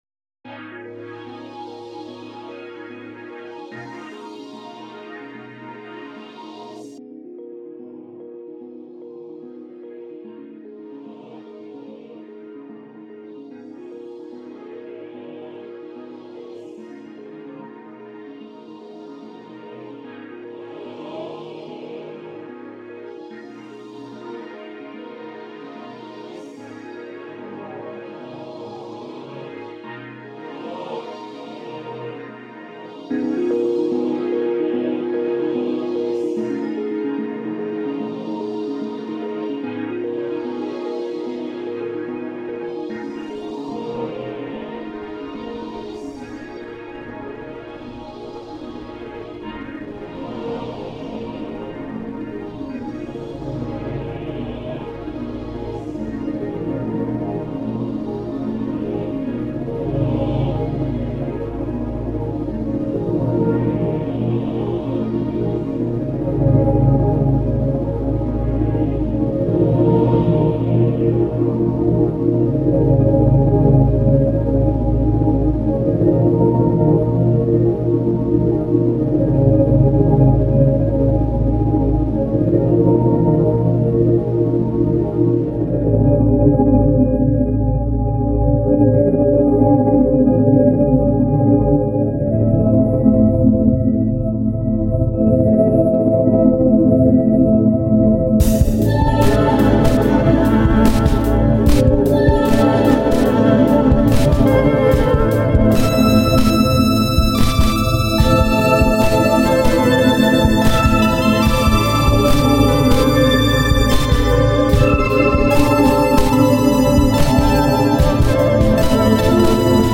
Spooky, yet satisfying!